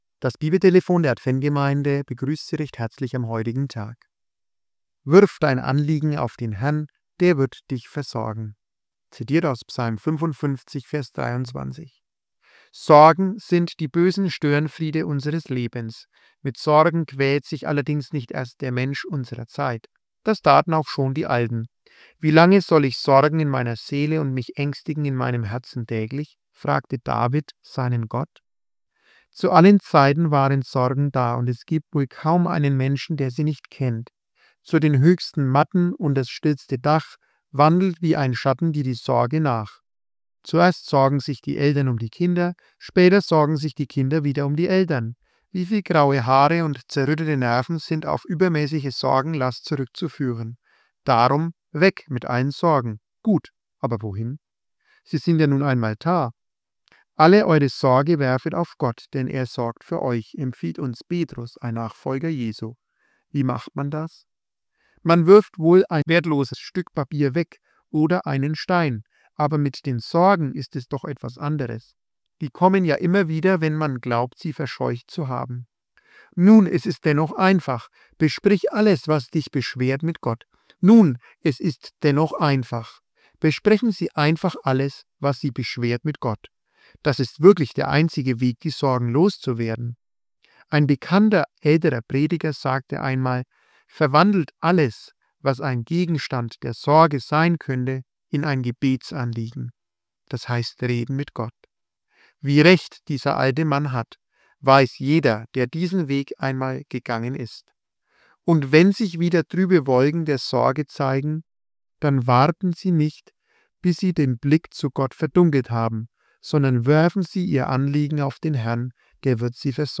Kurzandacht